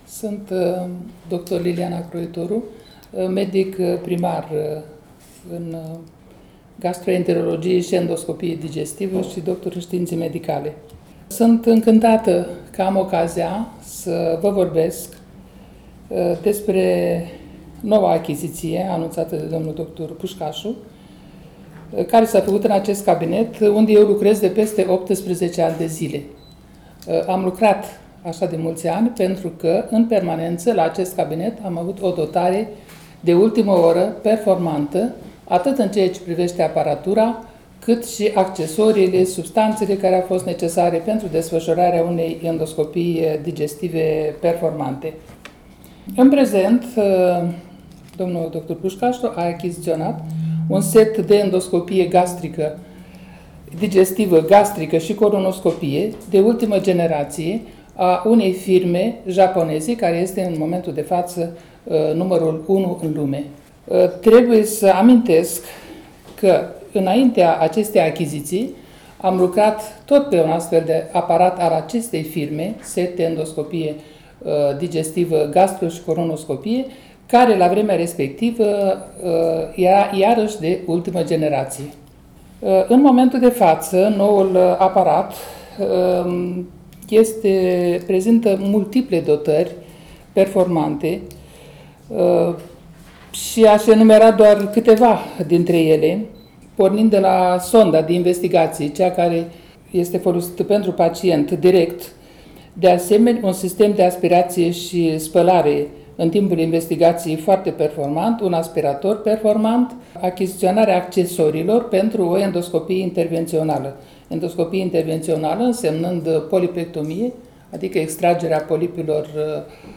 Spitalul Bethesda din Suceava – conferinţă de presă